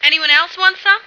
flak_m/sounds/female1/int/F1wantsome.ogg at ac4c53b3efc011c6eda803d9c1f26cd622afffce